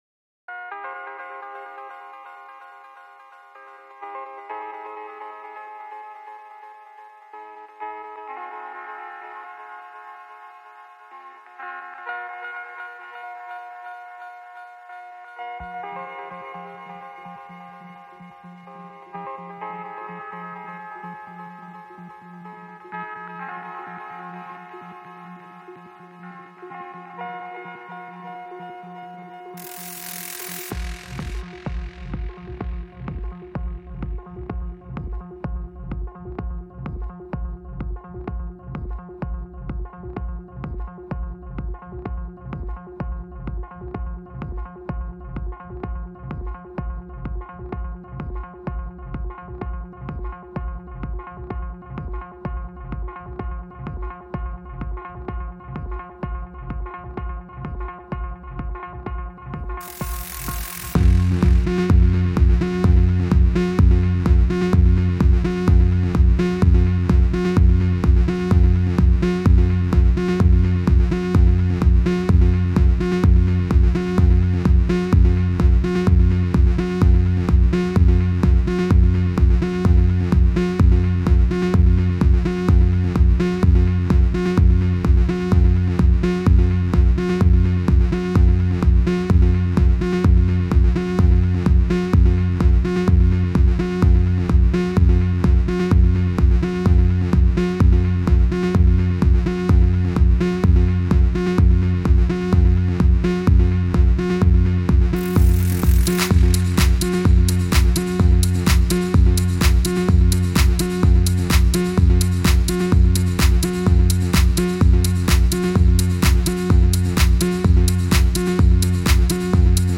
Some Techno is needed to get along with the new situation